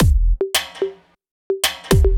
106 BPM Beat Loops Download